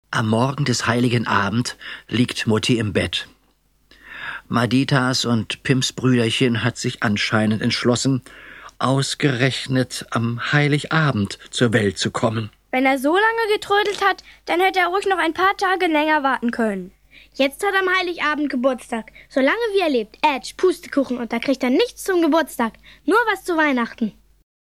Hörbuch: Madita 2. Madita und Pims.